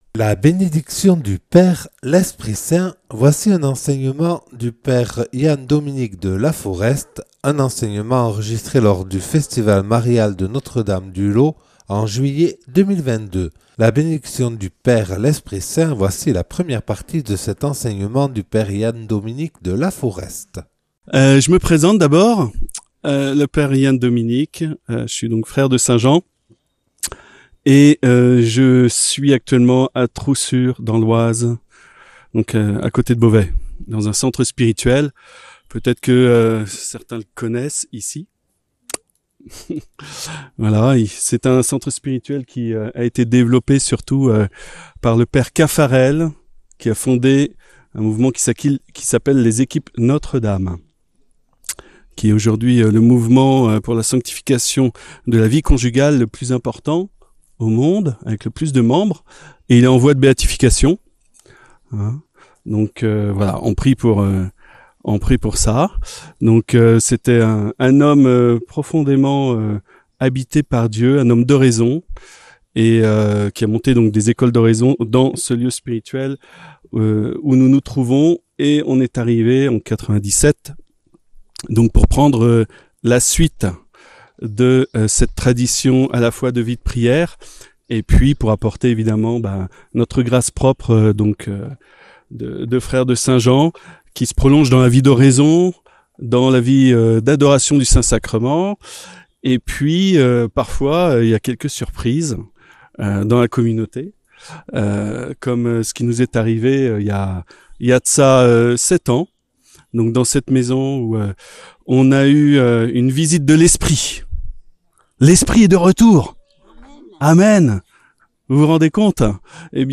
Une conférence